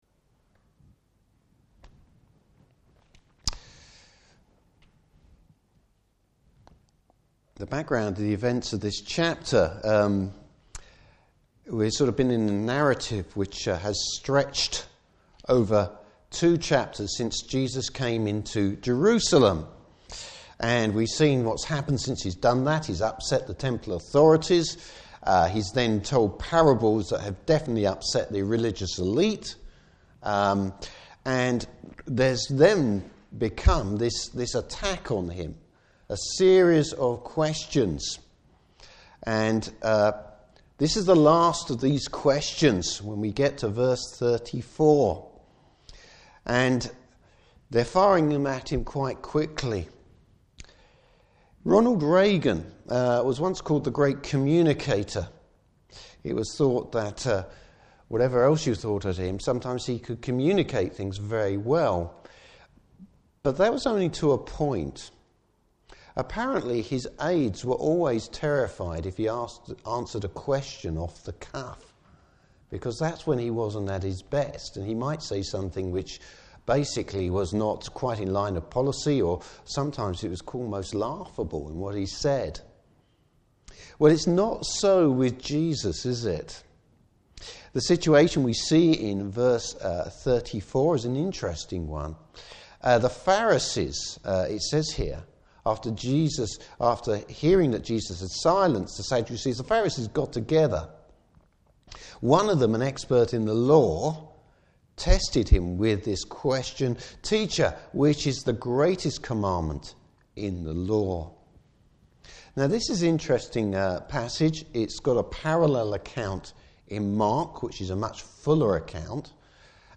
Service Type: Morning Service The nature of the Messiah. Topics: Jesus and the Old Testament Law.